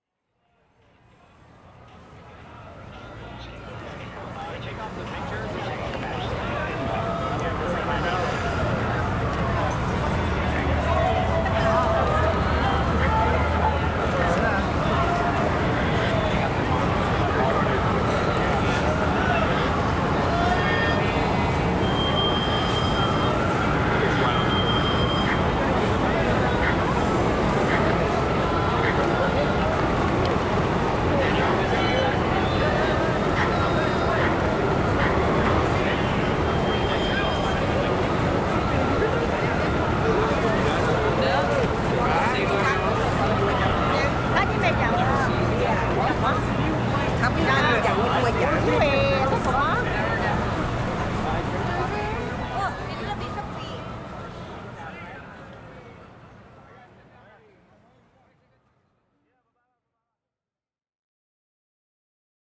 Pour cet exemple, j’ai sélectionné 2 ambiances principales, l’une prise de nuit au marché d’Ameyoko à Tokyo et l’autre de jour à Times Square.
Compression et panoramique
Pour donner un peu plus de coffre et de profondeur à l’ensemble, j’applique sur les deux bus une légère compression.
Ce plugin va élargir la stéréo de la piste.
Vous remarquez aussi que j’ai pas mal coupé les basses fréquences pour éviter de faire ronfler trop l’ambiance.
03-Tokyo-New-York-compression-et-space.wav